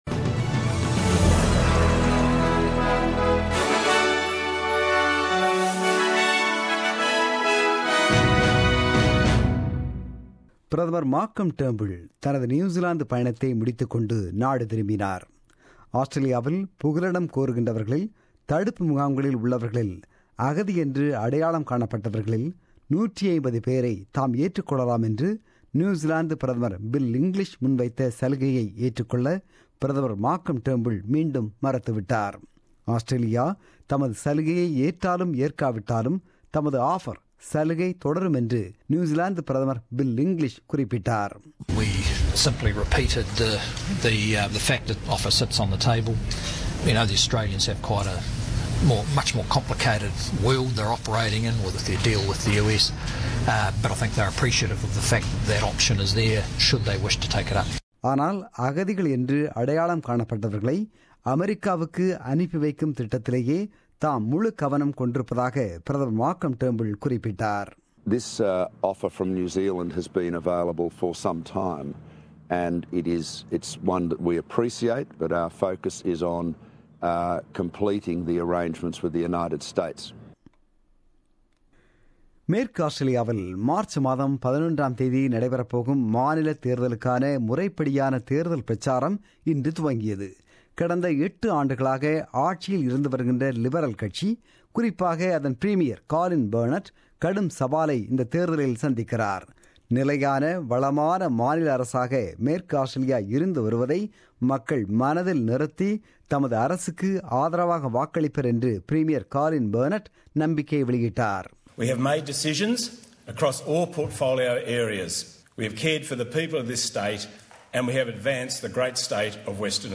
The news bulletin broadcasted on 19 February 2017 at 8pm.